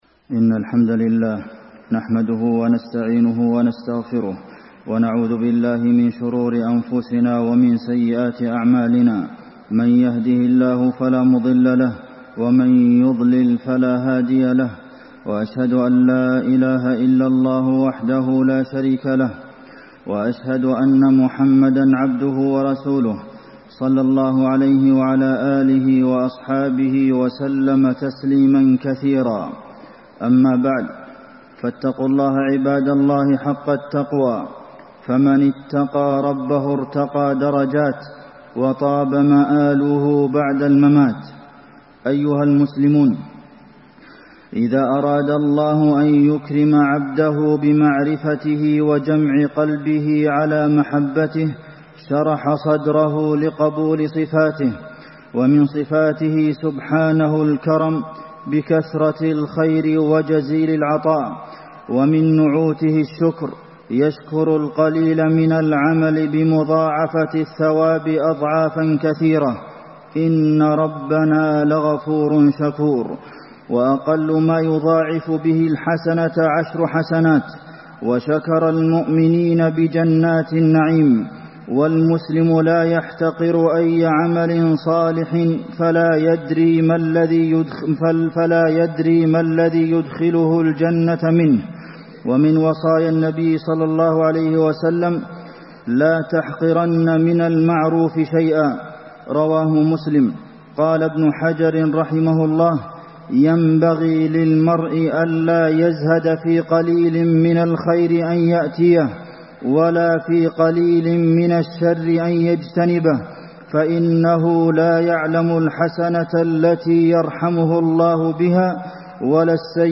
تاريخ النشر ٢٥ ربيع الأول ١٤٣٣ هـ المكان: المسجد النبوي الشيخ: فضيلة الشيخ د. عبدالمحسن بن محمد القاسم فضيلة الشيخ د. عبدالمحسن بن محمد القاسم نعم الله على عباده